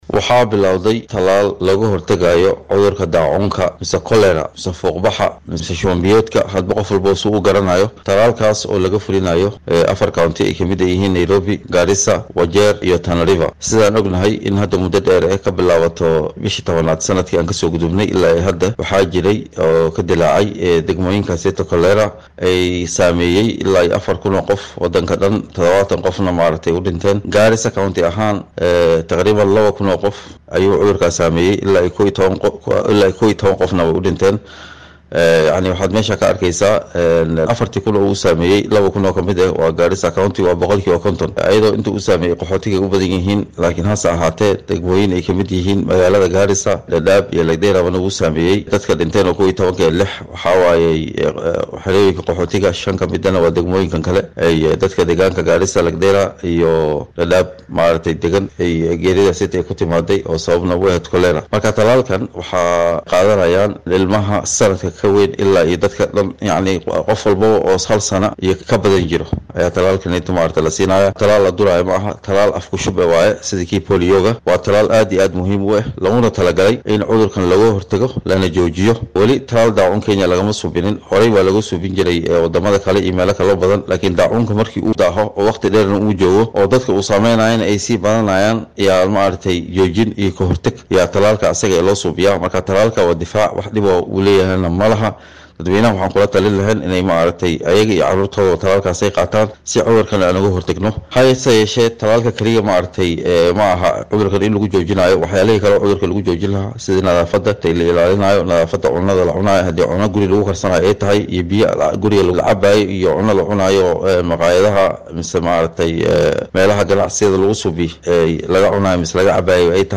Wasiirka wasaaradda caafimaadka iyo fayadhowrka ismaamulka Garissa Axmednathir Cumar ayaa ka hadlay tallaalka ka dhanka ah cudurka daacuunka oo ka dillaacay qaybo ka mid ah gobolka waqooyiga bari . Wasiirka ayaa ka codsaday dadweynaha in ay soo dhaweeyaan howlwadeennada ka shaqeynaya tallaalka wuxuuna meesha ka saaray inay dhibaato ka imaan karta tallaalka la bixinaya.